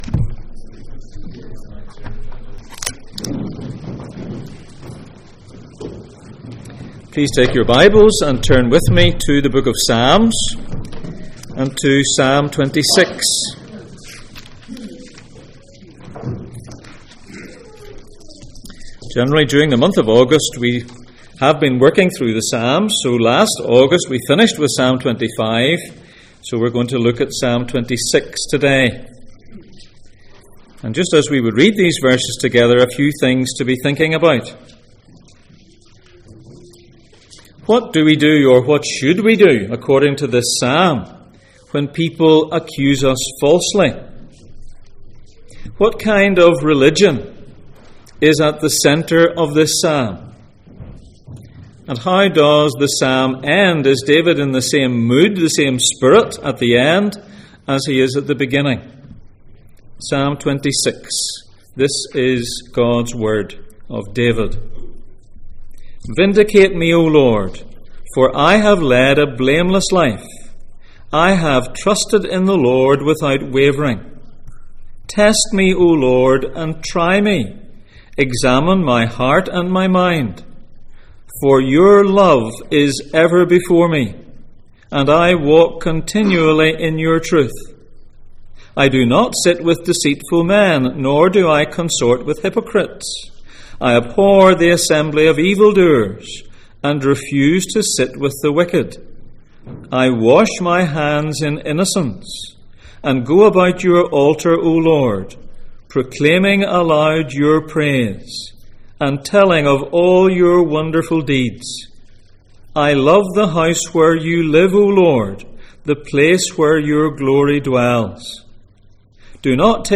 Psalms Passage: Psalm 26:1-12 Service Type: Sunday Morning %todo_render% « A fearful price What do you do with recurring fear?